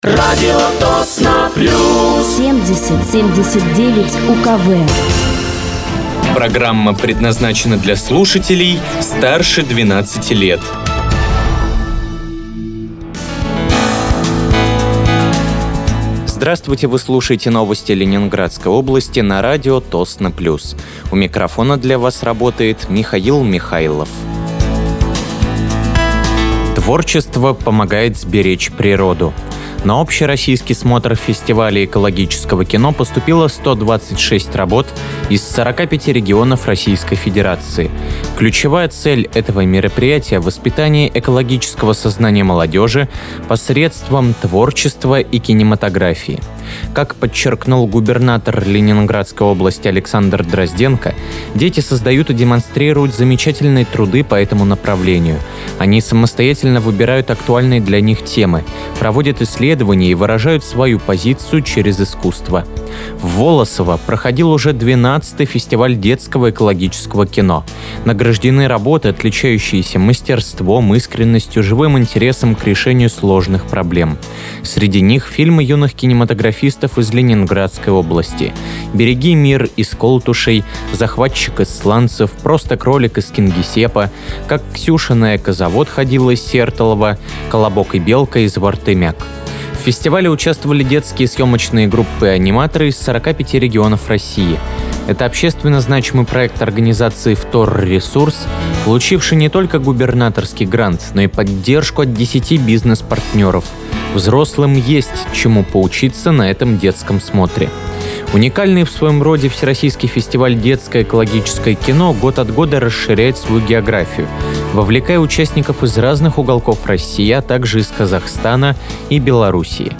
Вы слушаете новости Ленинградской области от 24.10.2025 на радиоканале «Радио Тосно плюс».